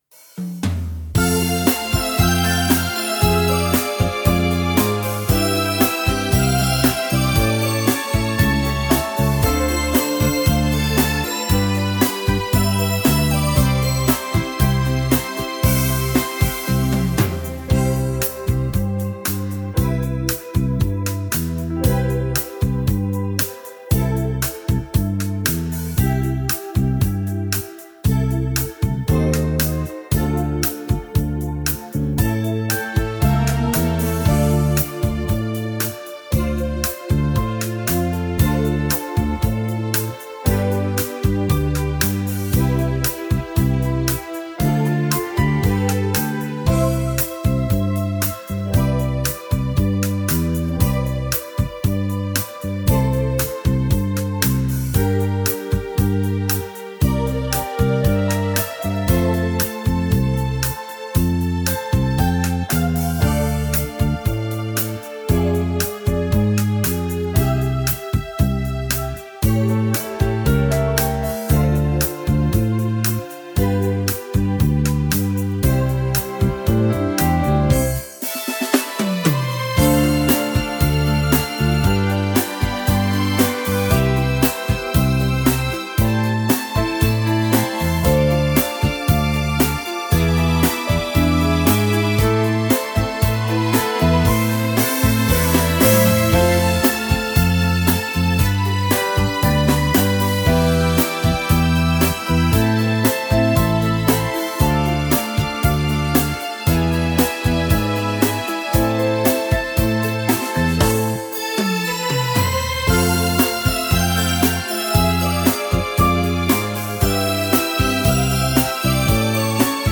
ballo lento romantico fantastico